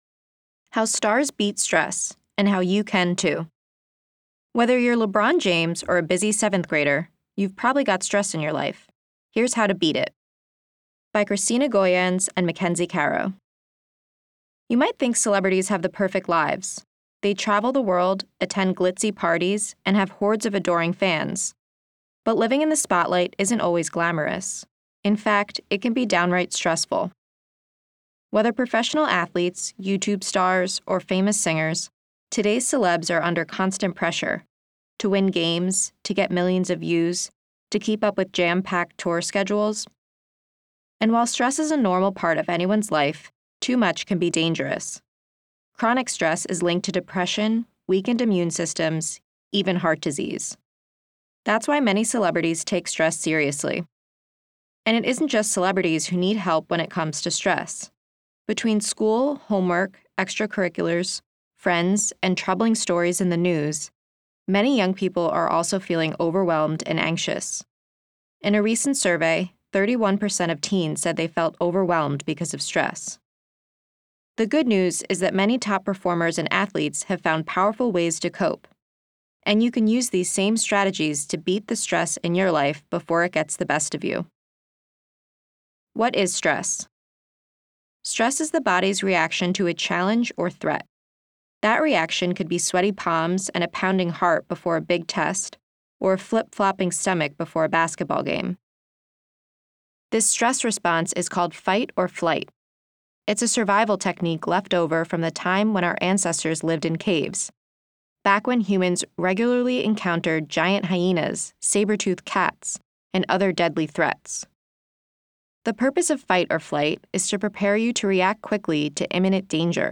audio version while students follow along in their printed issues.